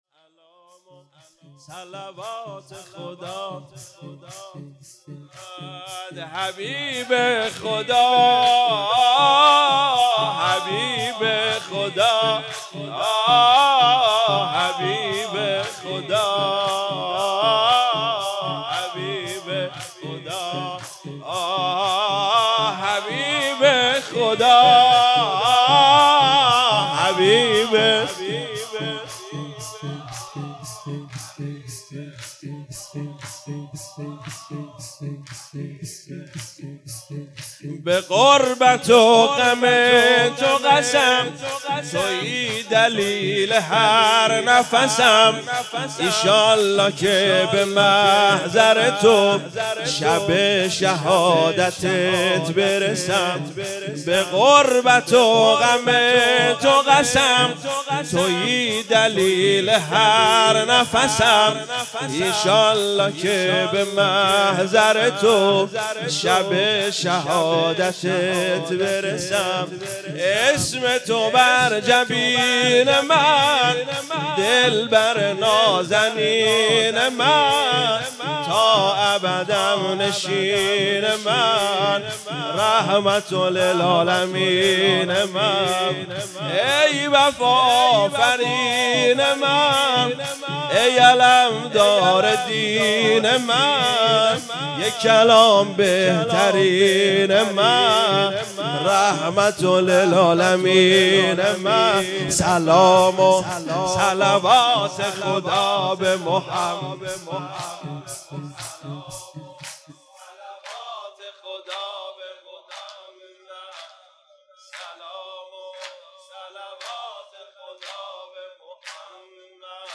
مراسم دهه آخر صفر98 - شب اول 4 آبان 98